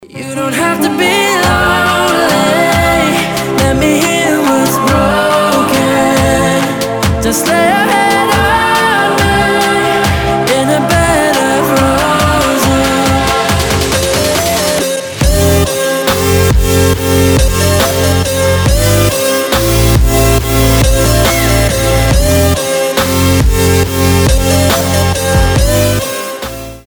• Качество: 320, Stereo
мужской вокал
красивые
dance
Electronic
EDM
романтичные
future bass